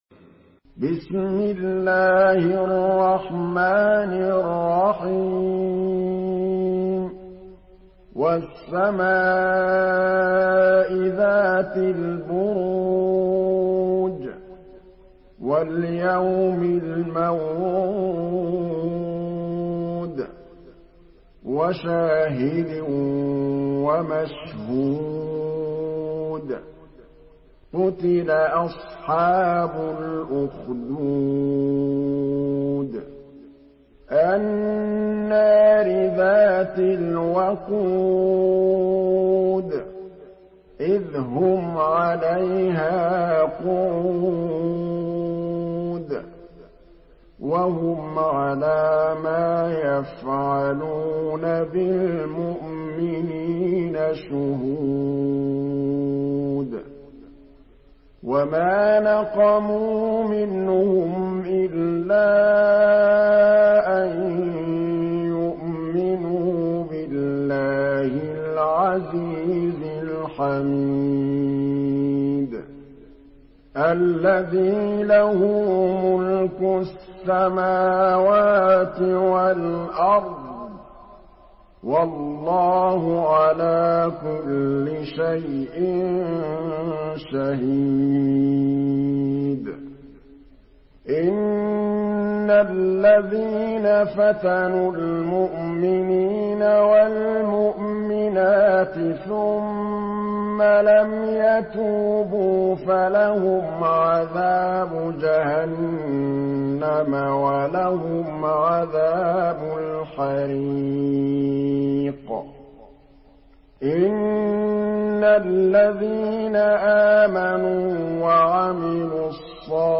سورة البروج MP3 بصوت محمد محمود الطبلاوي برواية حفص
مرتل